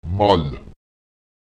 Lautsprecher mal [mal] der Geliebte, der Partner, der Gefährte